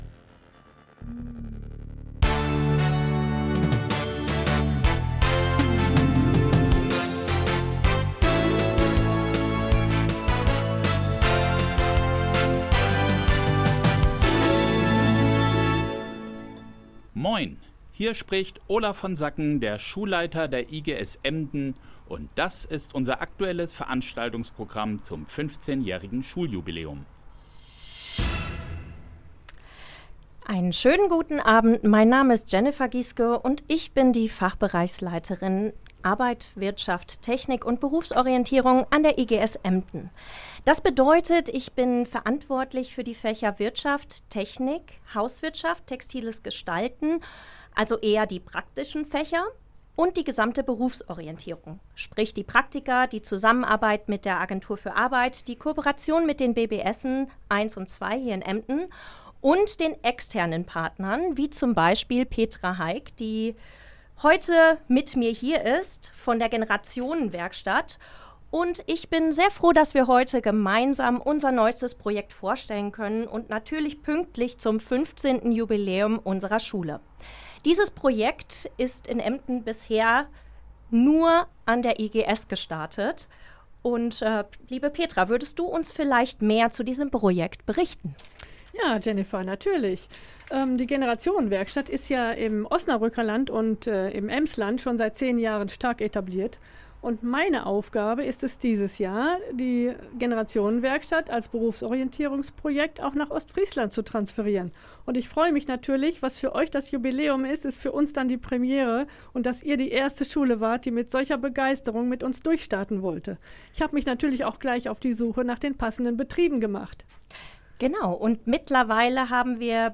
Am Mittwoch, den 19.11.2025, sendete Radio-Ostfriesland eine Life-Übertragung zur GenerationenWerkstatt.